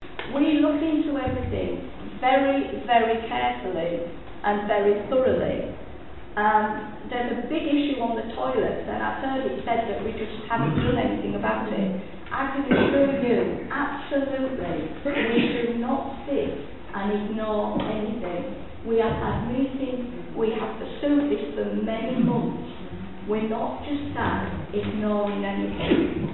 At the Full Council (Budget) Meeting on 8th January 2024,
a very interesting statement was made by Councillor TURNER – and, having known Sandra personally for some twenty years, I have no doubt whatsoever that it was made in the very epitome of good faith – which is why it struck me at the time as highly significant (and why it has been drawn to the attention of the External Auditor, PKF LITTLEJOHN LLP).